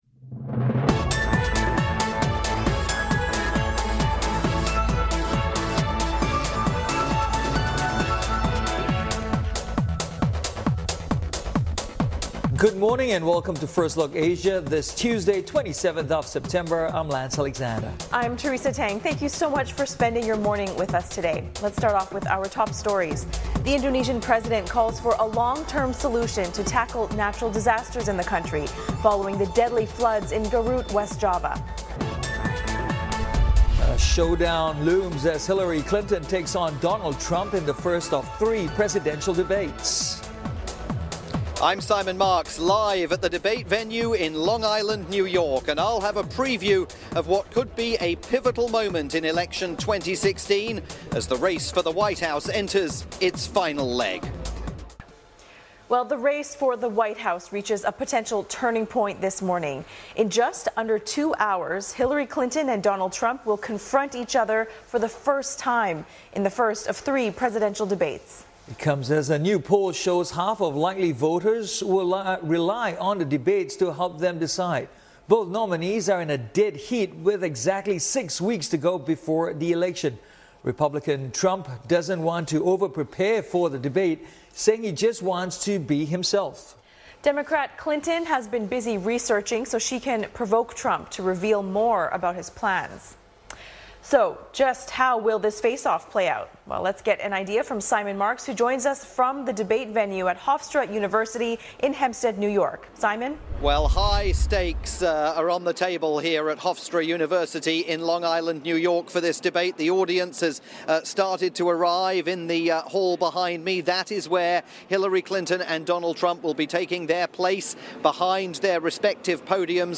live coverage of the first Presidential debate between Hillary Clinton and Donald Trump